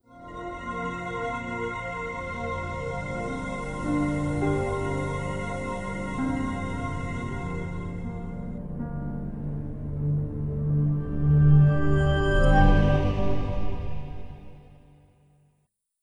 Windows NT 10.0 Startup.wav